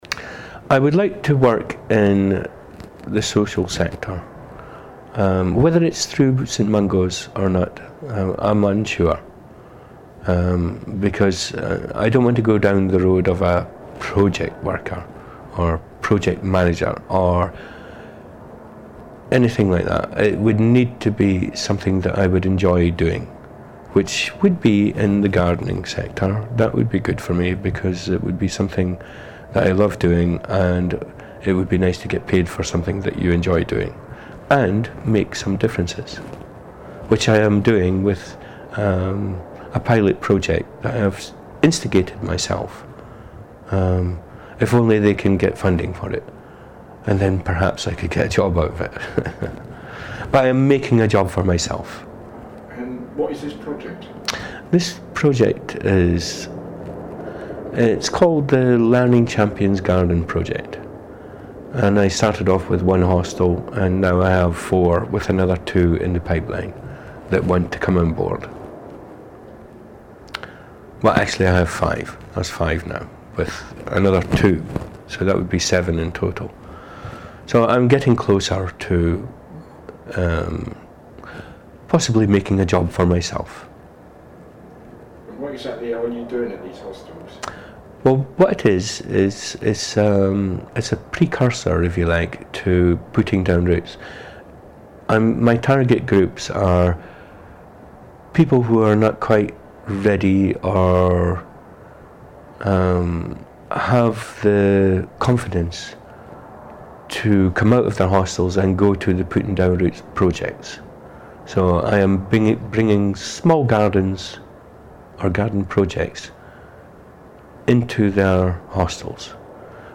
Some interviews contain strong language.